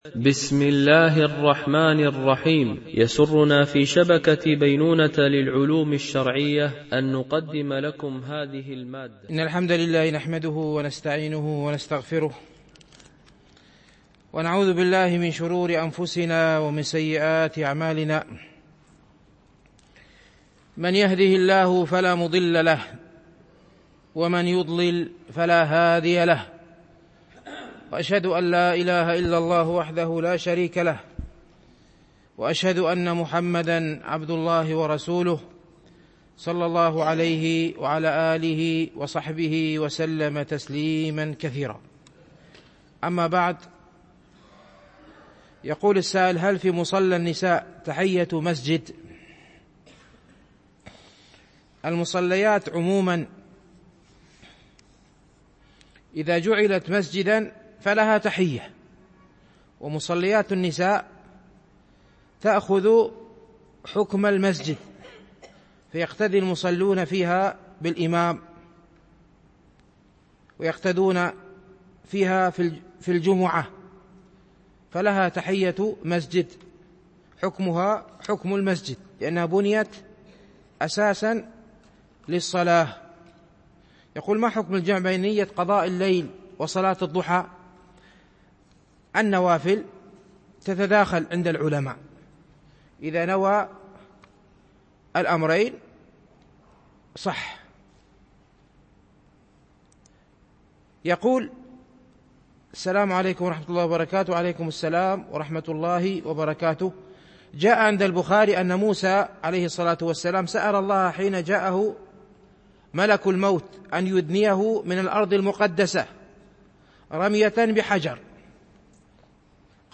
شرح رياض الصالحين – الدرس 307 ( الحديث 1192 - 1196)